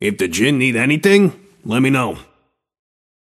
Shopkeeper voice line - If the Djinn need anything, let me know.